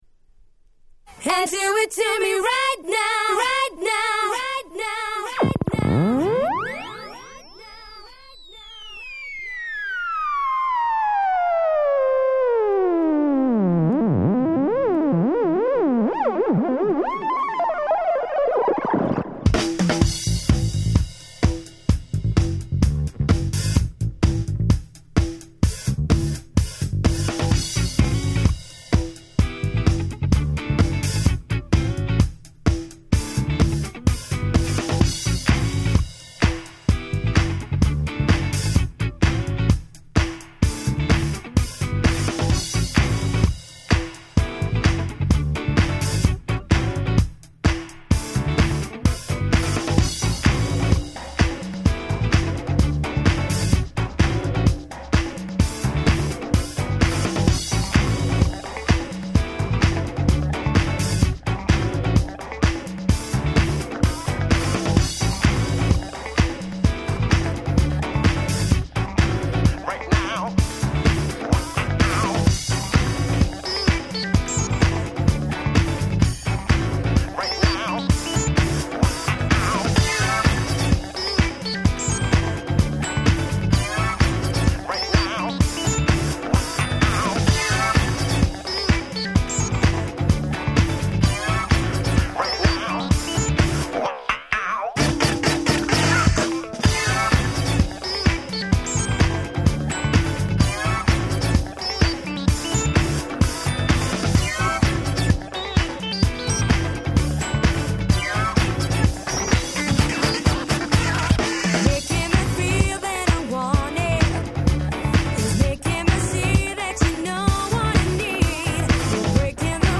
00' キャッチーR&B !!
若干BPMは早目ですが、この溢れ出るキャッチーさ、最高です！！